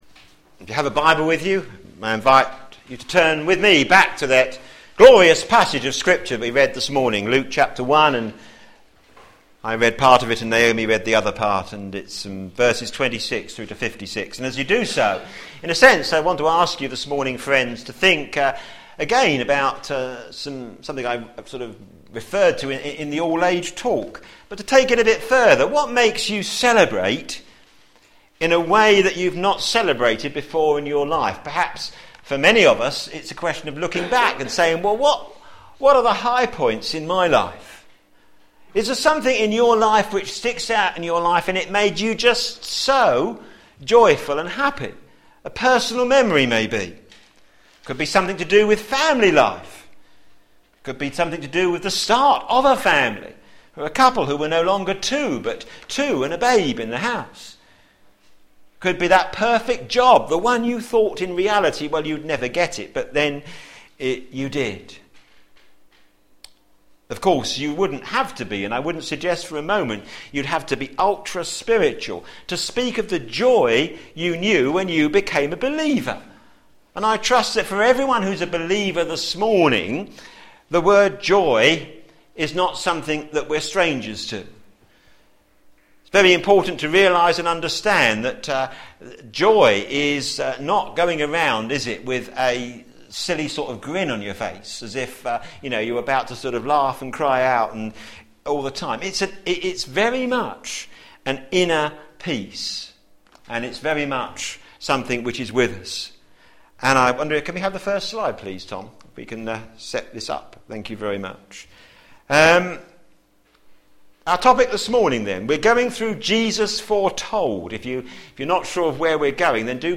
Media for a.m. Service on Sun 12th Dec 2010 10:30
Theme: Jesus Foretold in joyful praise Sermon